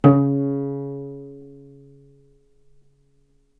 vc_pz-C#3-ff.AIF